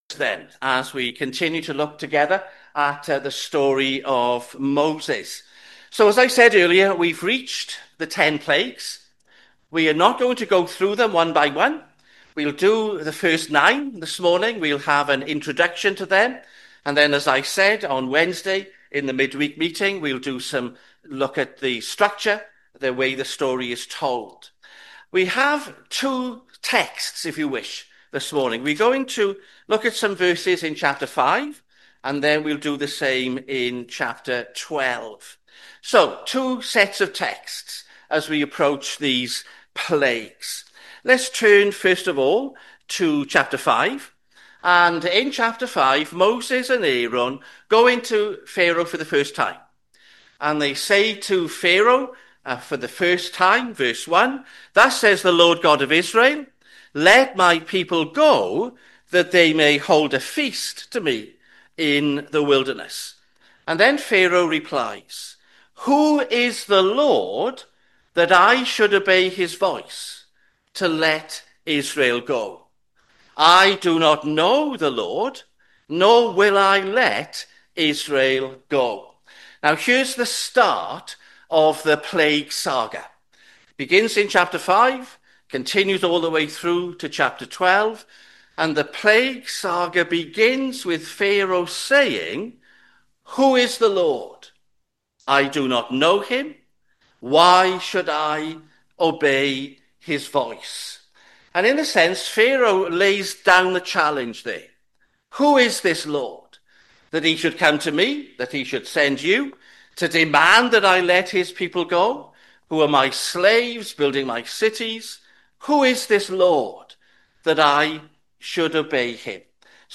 Sermons Tabernacle Church - 27/7/25 am - The Plagues Play Episode Pause Episode Mute/Unmute Episode Rewind 10 Seconds 1x Fast Forward 30 seconds 00:00 / 32:20 Subscribe Share RSS Feed Share Link Embed